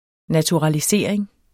Udtale [ natuʁɑliˈseˀʁeŋ ]